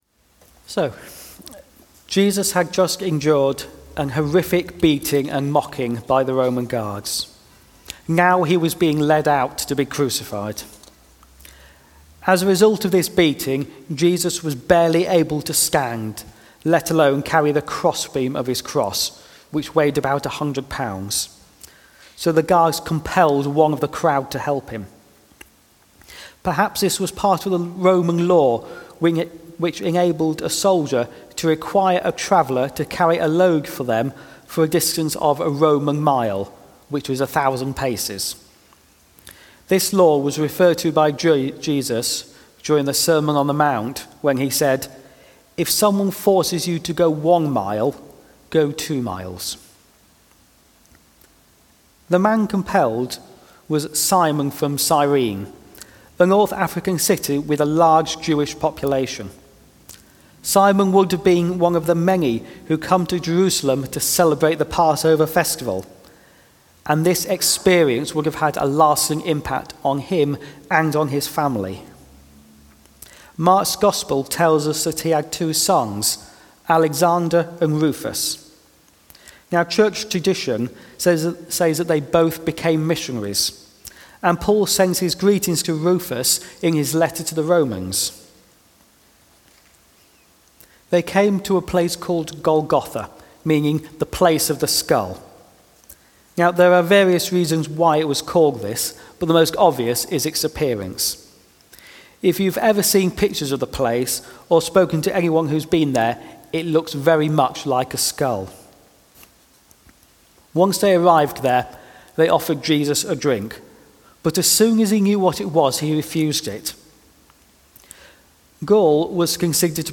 Eastgate Union Good Friday sermon 2025